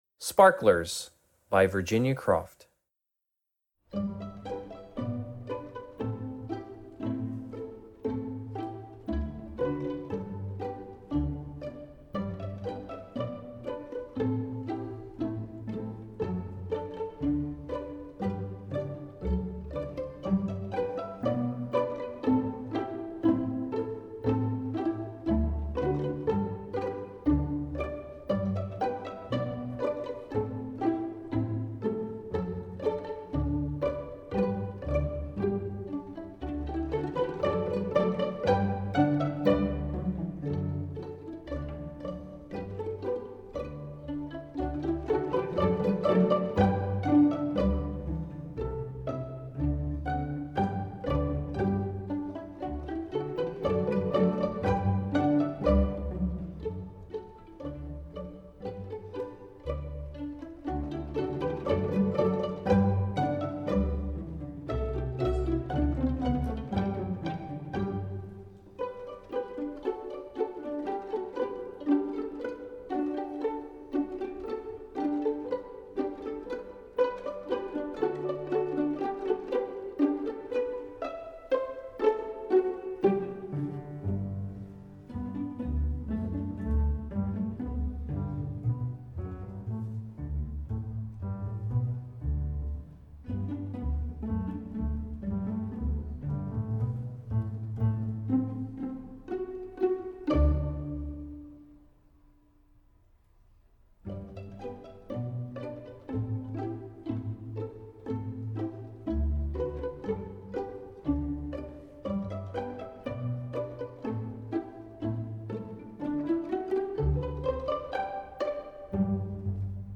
Voicing: String Orchestra S